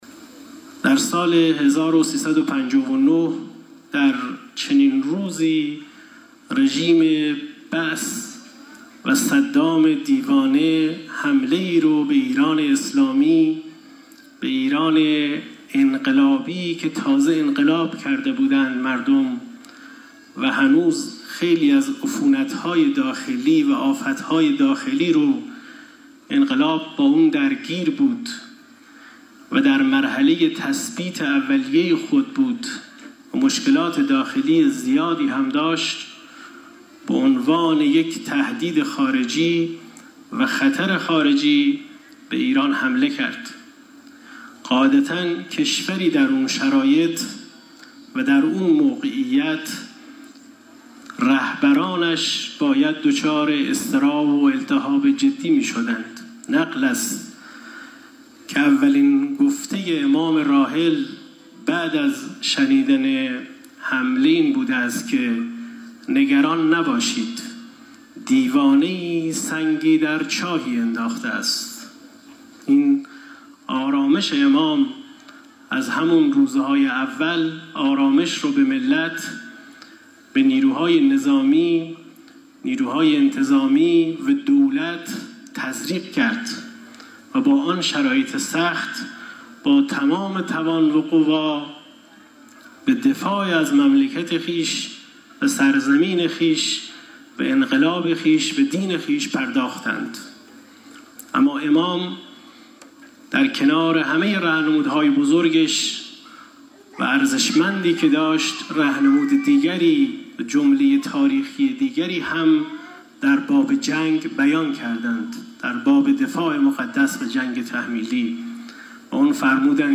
استاندار لرستان:
به گزارش خبرنگار خبرگزاری رسا در خرم آباد، موسی خادمی، استاندار لرستان، صبح امروز در مراسم رژه نیروهای مسلح لرستان، گفت: در سال 59 در چنین روزی رژیم انقلاب اسلامی که در مرحله تثبیت اولیه خود بود و با وجود مشکلات داخلی روبرو بود، صدام به عنوان یک خطر خارجی به ایران حمله کرد.